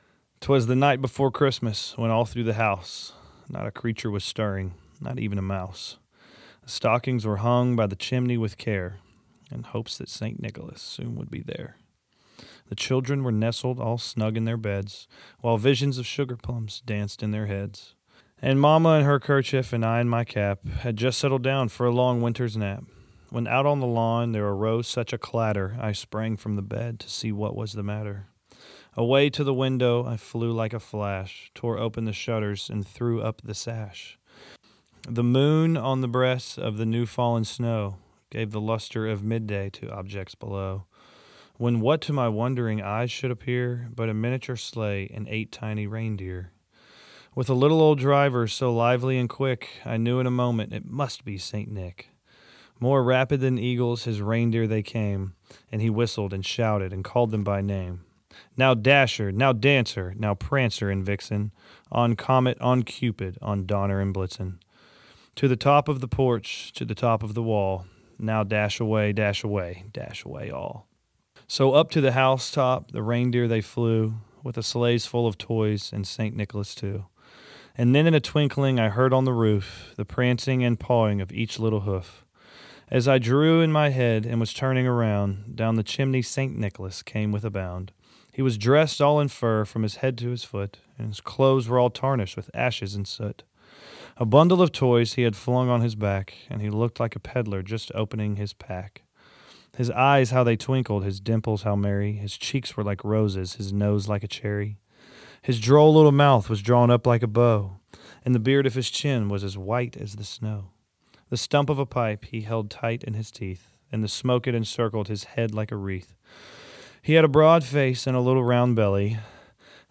Here’s a solo read of “‘Twas the Night Before Christmas” from Parker McCollum.